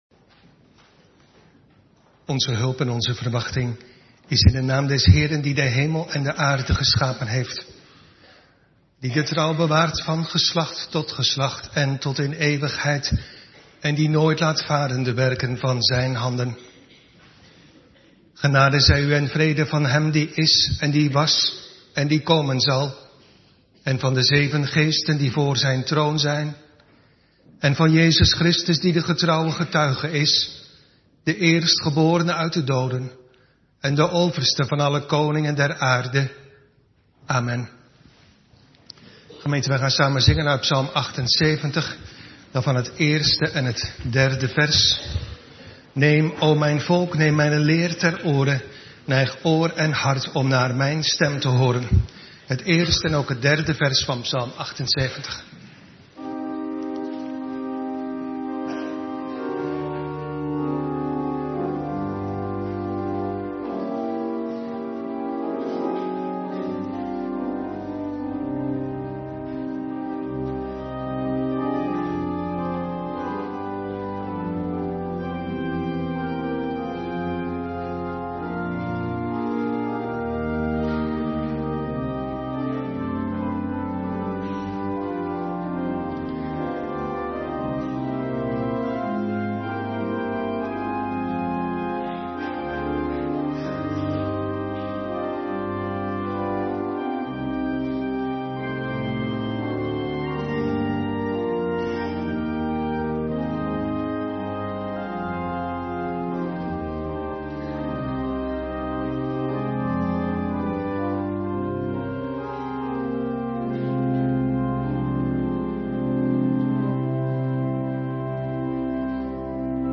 Preek Doopformulier (12): Doopvragen aan ouders